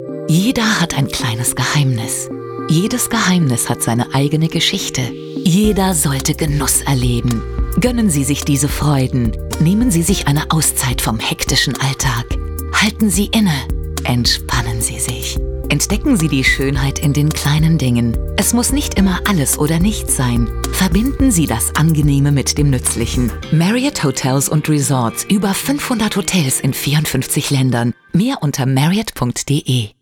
German. Actress, low and smooth, to upbeat.
Marriott Hotels Radio Commercial German